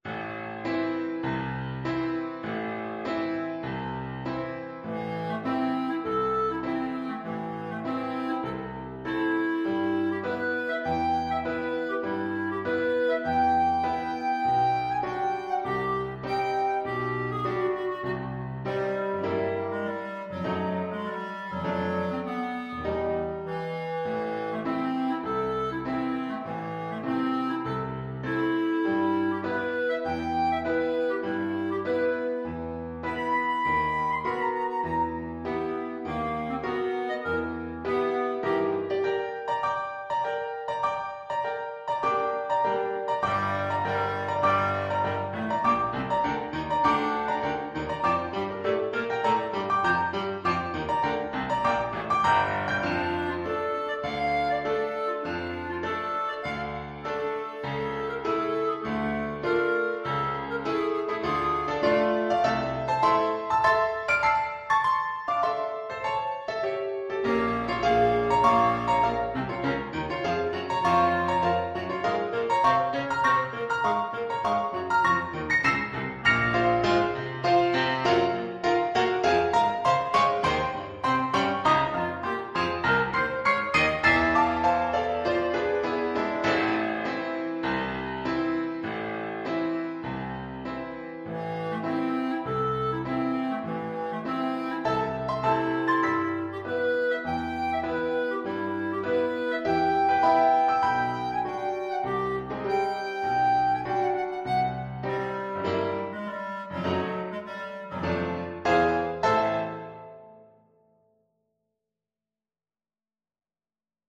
4/4 (View more 4/4 Music)
Classical (View more Classical Clarinet Music)